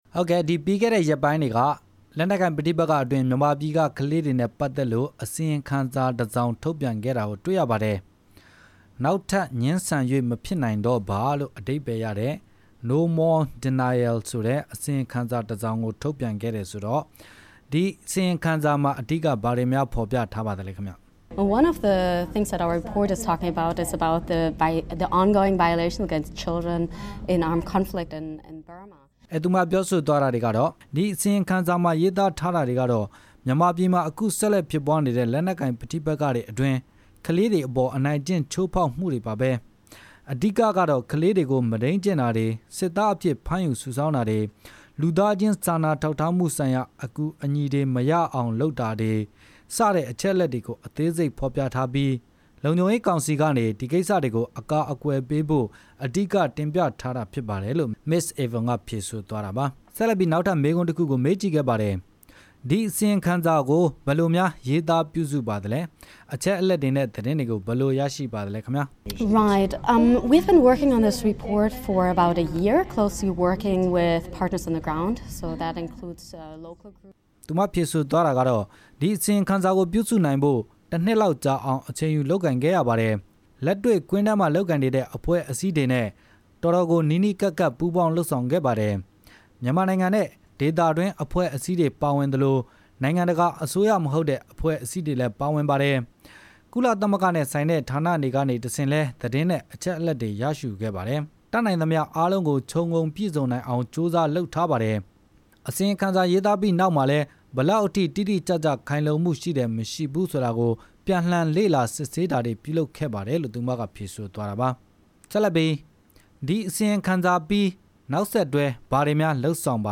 တြေႛဆုံမေးူမန်းခဵက်။